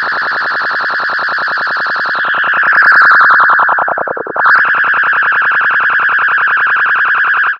Filtered Feedback 13.wav